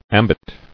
[am·bit]